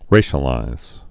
(rāshə-līz)